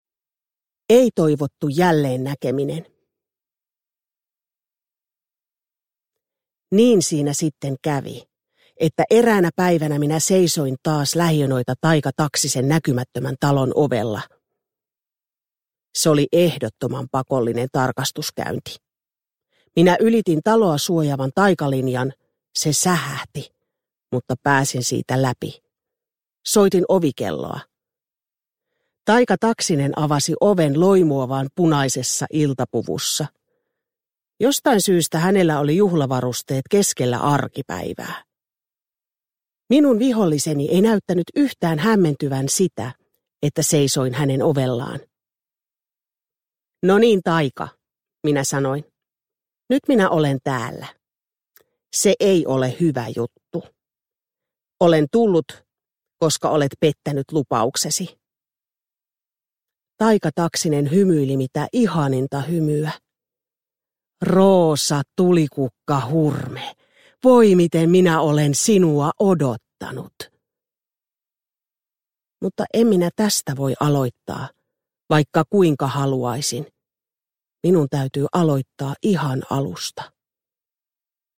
Uppläsare: Siri Kolu